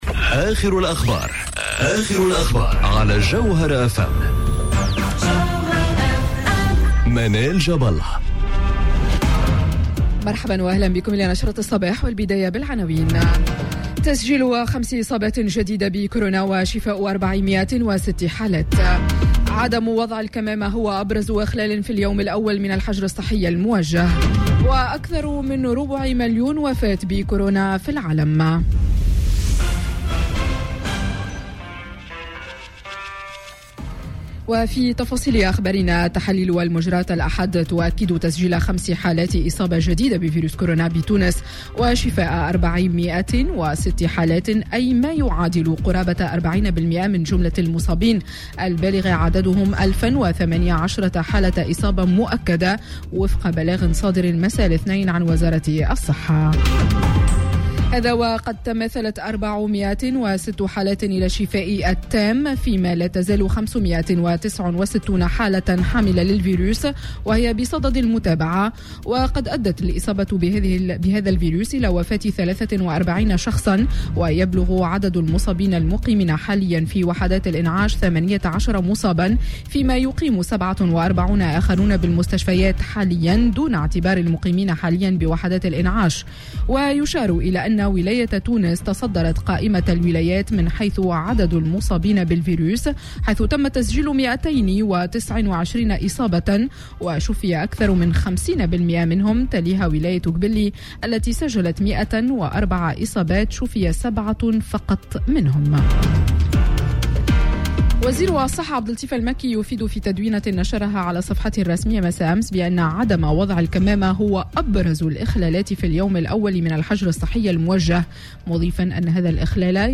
نشرة أخبار السابعة صباحا ليوم الثلاثاء 05 ماي 2020